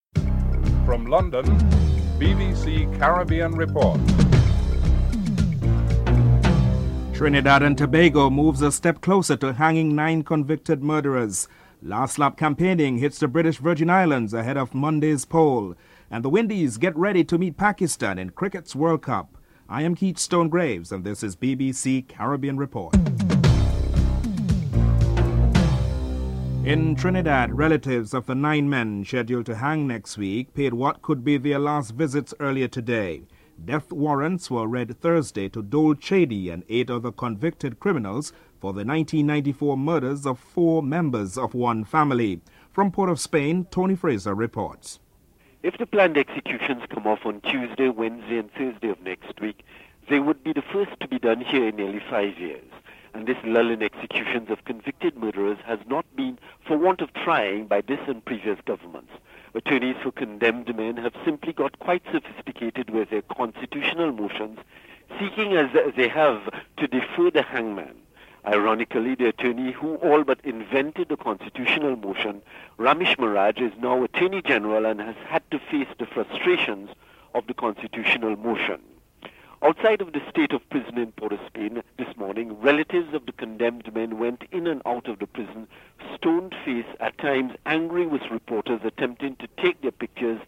5. Saint Kitts and Nevis Prime Minister Denzil Douglas outlines challenges facing regional Foreign Ministers at a meeting in Basseterre.